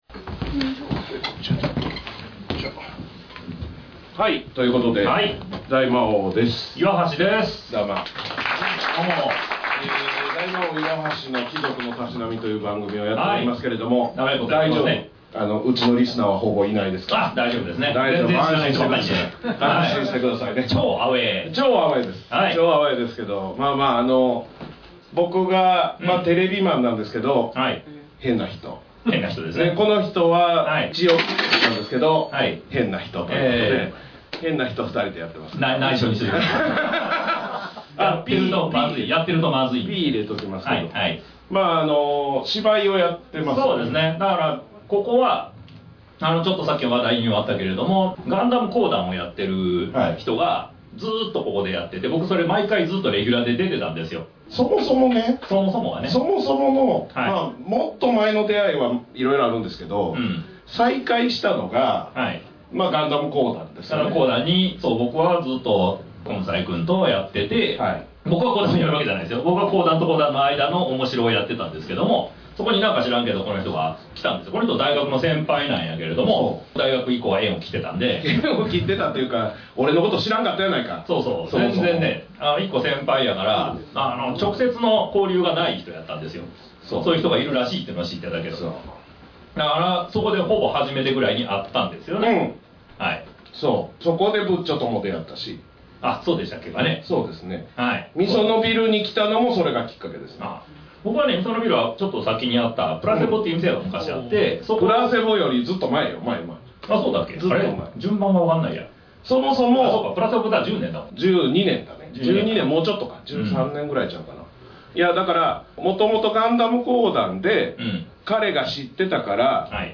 ・12/30「サラバ味園！Podcast大収録会」にて収録